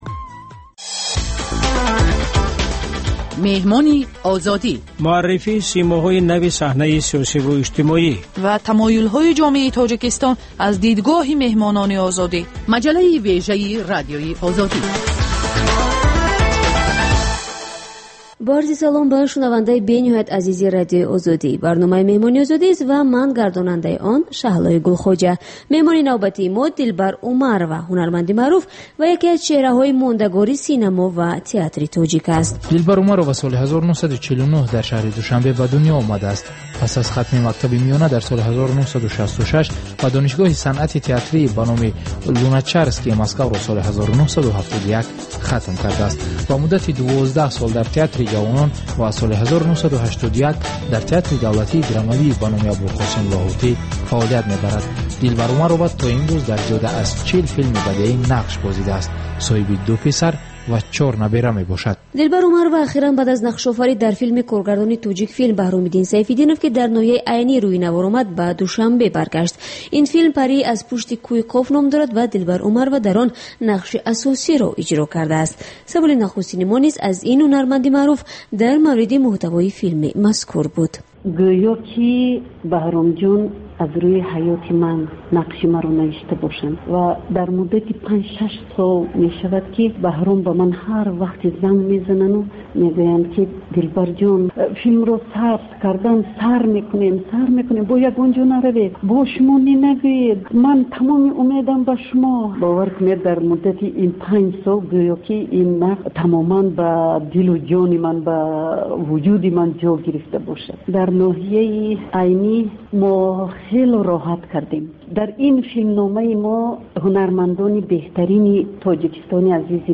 Гуфтугӯи ошкоро бо шахсони саршинос ва мӯътабари Тоҷикистон.